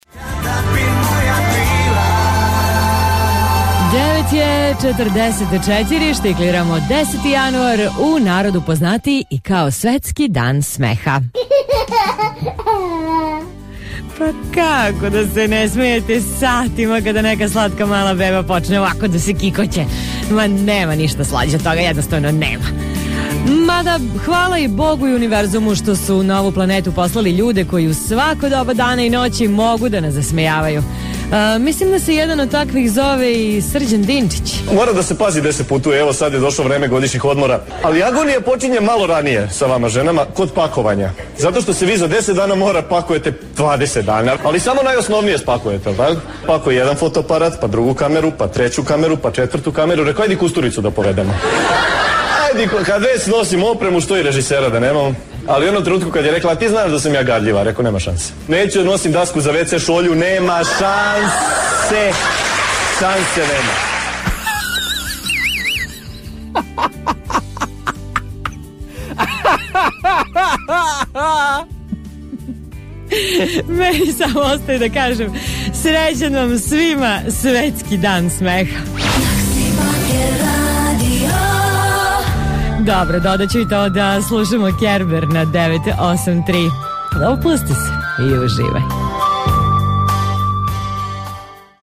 stand up